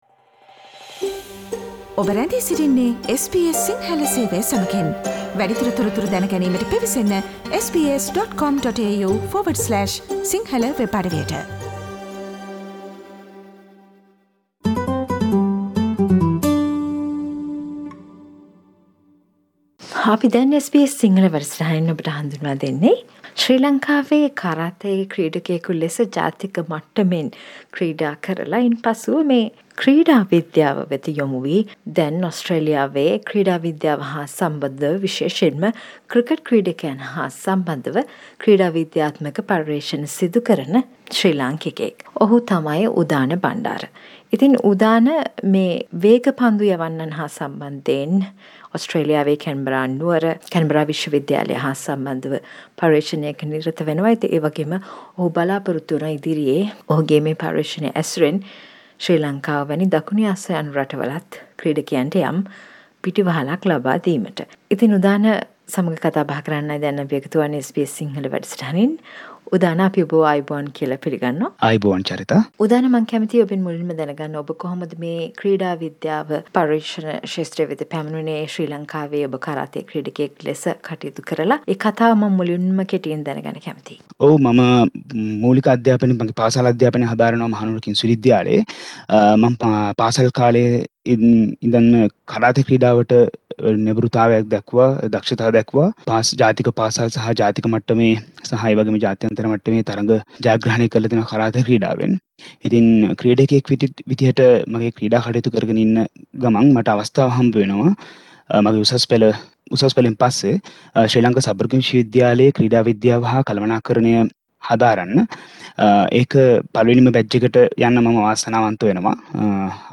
SBS සිංහල සේවය සිදු කල පිළිසඳරට සවන් දෙන්න.